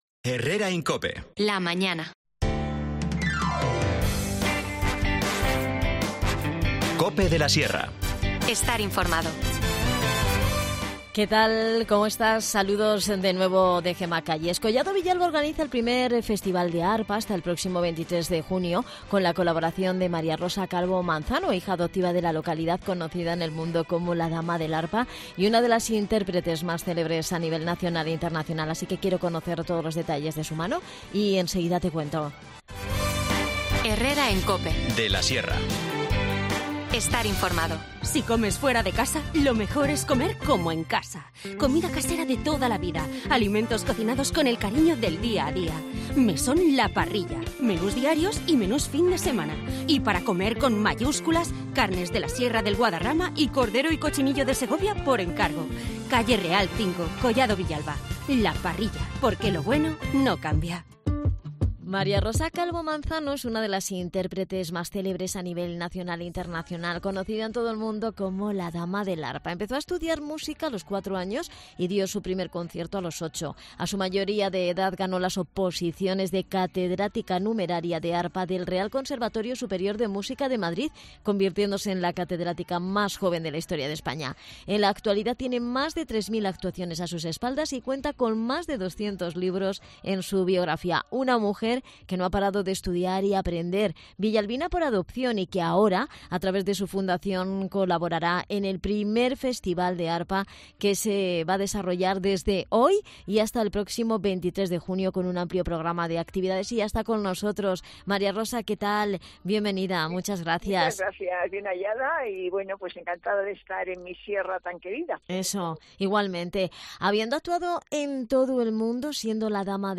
Redacción digital Madrid - Publicado el 17 abr 2023, 13:18 - Actualizado 17 abr 2023, 13:34 2 min lectura Descargar Facebook Twitter Whatsapp Telegram Enviar por email Copiar enlace Hoy hablamos en el programa con la Dama de Arpa e hija adoptiva de Collado Villalba, María Rosa Calvo Manzano.